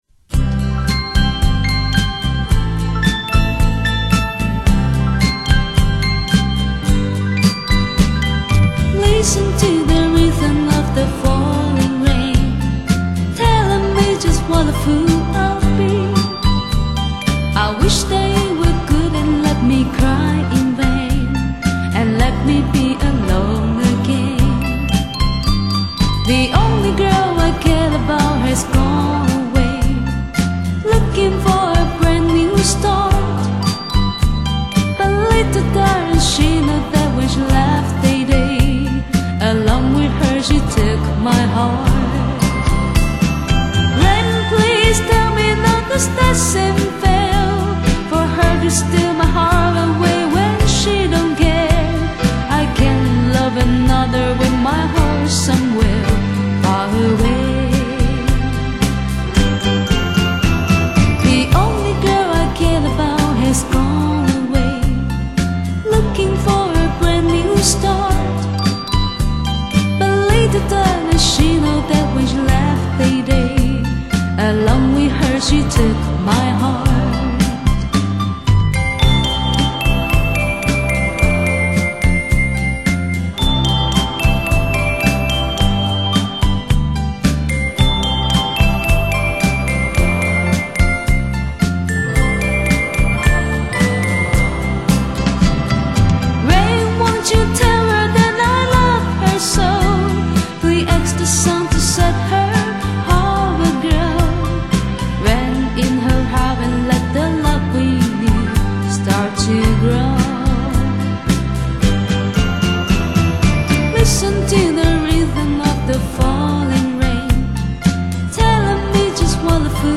语    种：纯音乐
[广告语] 乡村宁静雅致，民谣清新质朴。